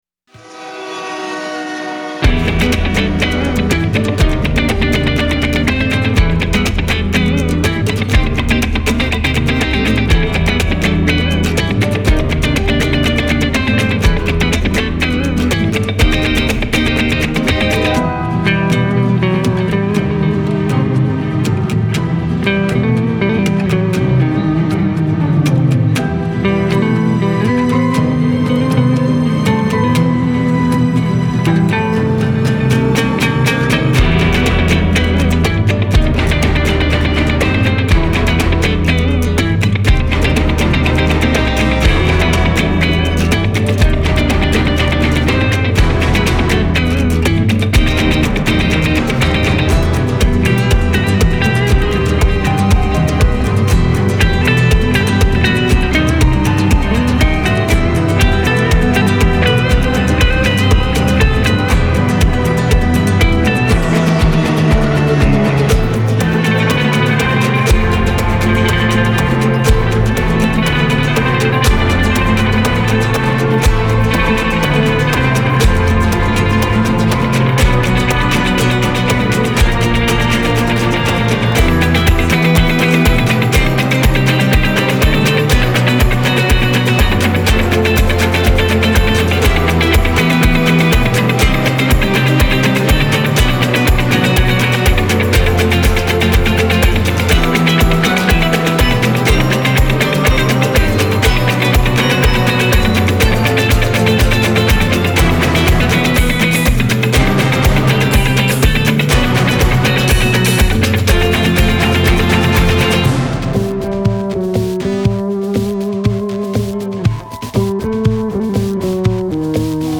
Genre : Latin